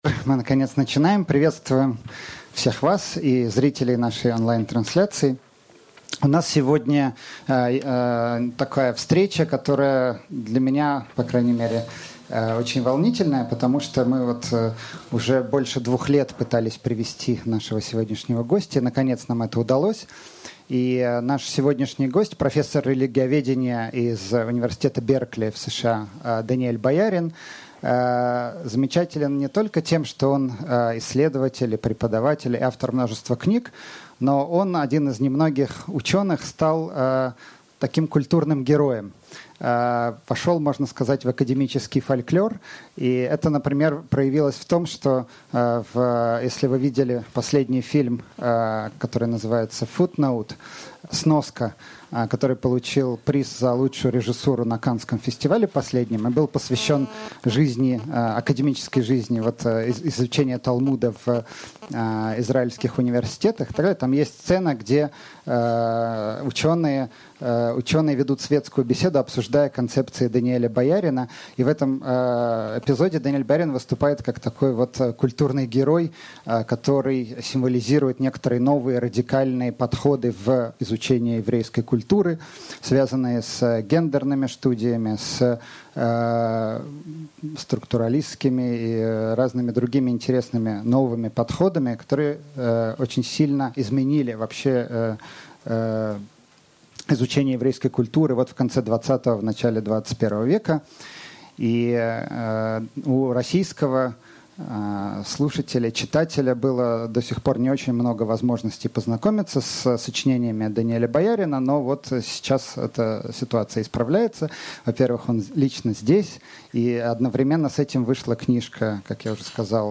Aудиокнига Иисус соблюдал кашрут Автор Даниэль Боярин.